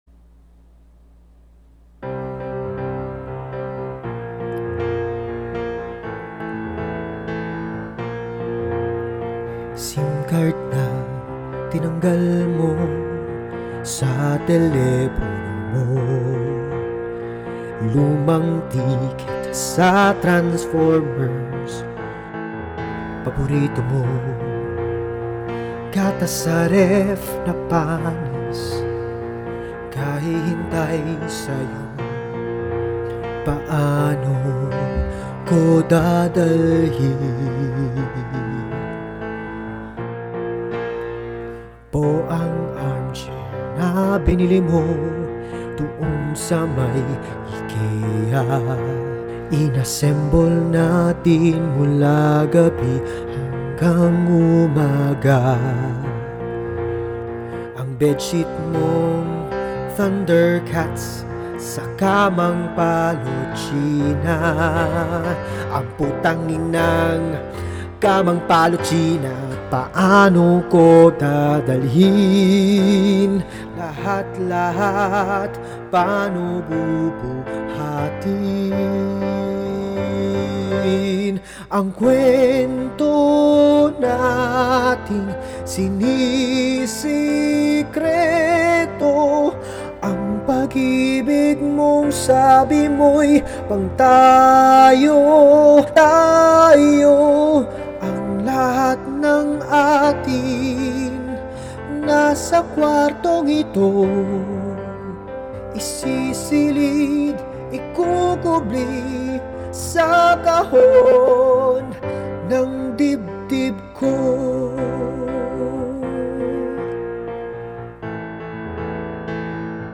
Dito sa Empake, nagsasagutan ang dalawang personang nasa punto na ng paghihiwalay.  May patama sa mga palihim na relasyon, sa mga pagdadala ng kanya-kanyang bagahe, at attachment sa mga gamit dahil sa ikinakabit na mga damdamin at alaala.